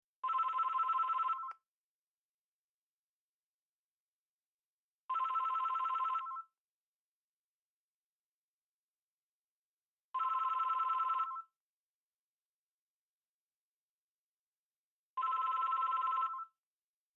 Kategorie Telefon